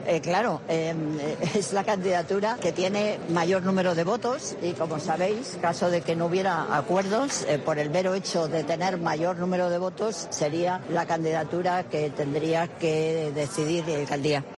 Declaraciones de la alcaldesa en funciones, tras descubrir una placa con el nombre de Eduardo Arroyo en la fachada del número 19 de la calle de Argensola, lugar donde nació el reconocido pintor.